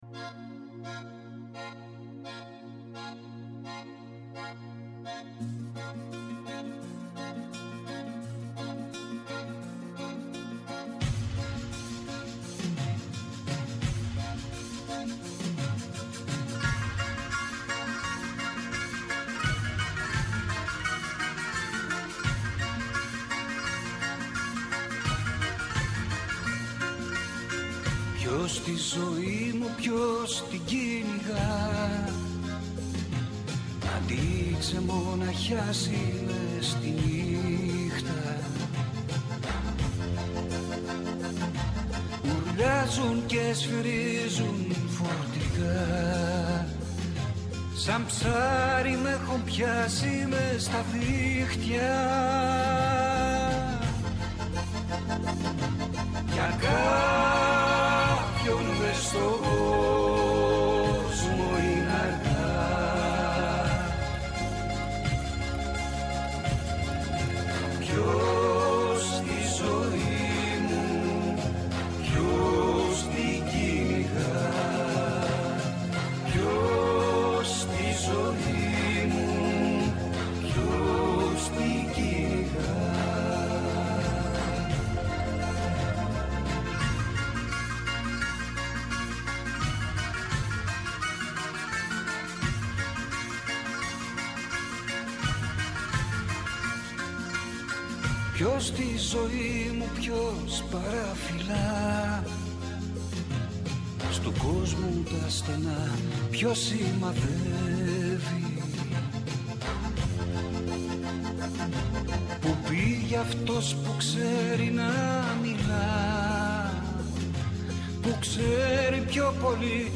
Η Σωστή Ώρα στο Πρώτο Πρόγραμμα της Ελληνικής Ραδιοφωνίας